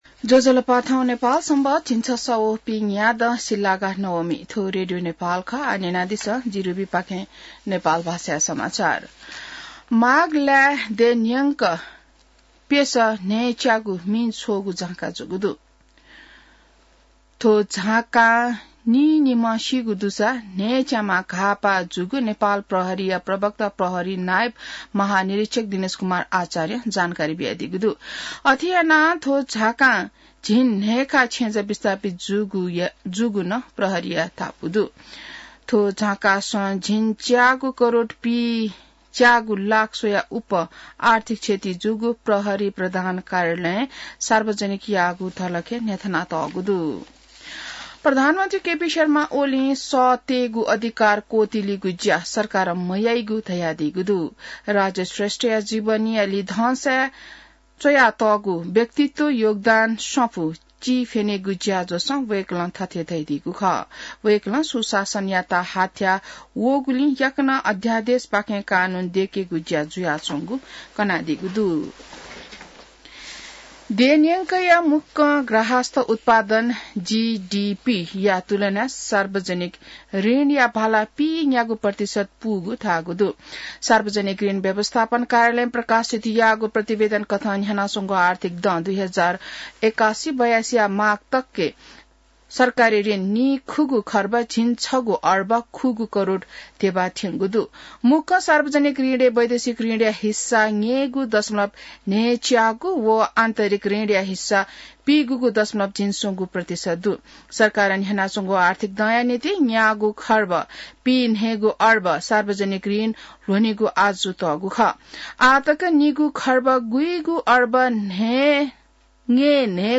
नेपाल भाषामा समाचार : ११ फागुन , २०८१